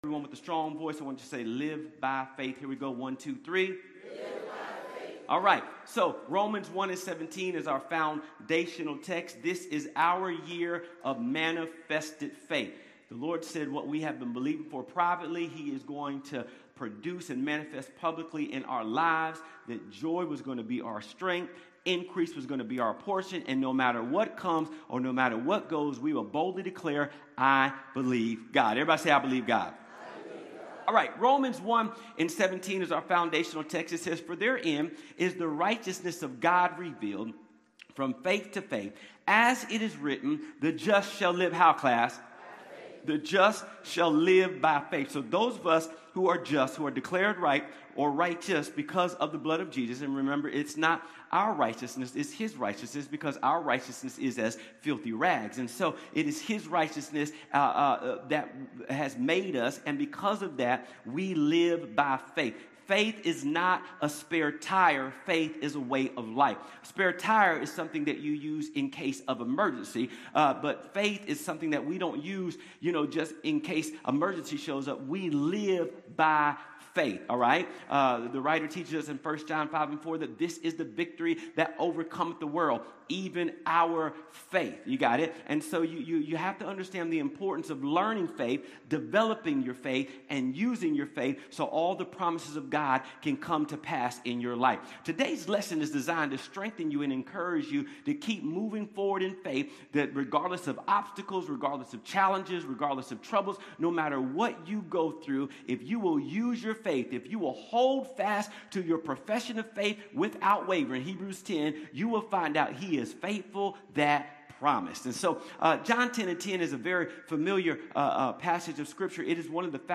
Connect Groups Events Watch Church Online Sermons Give Live By Faith January 18, 2026